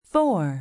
Index of /phonetones/unzipped/LG/KE990-Viewty/Keytone sounds/Piano